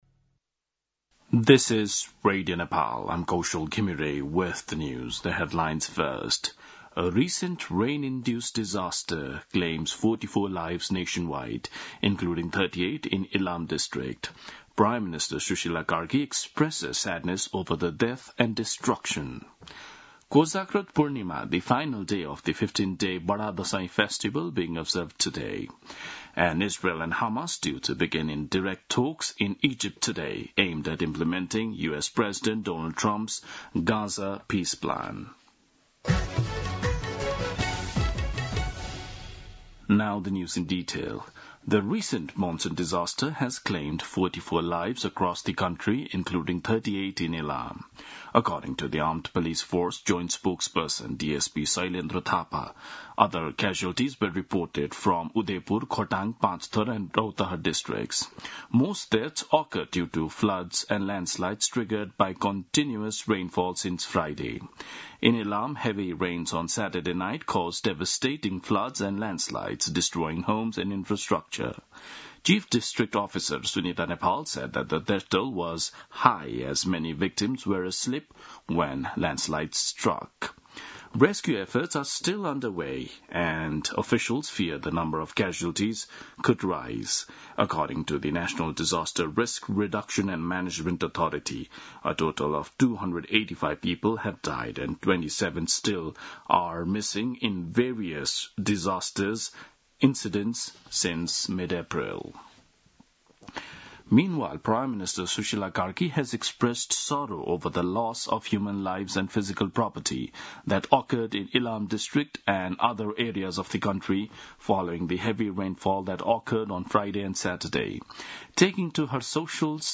2 PM English News : 03 May, 2026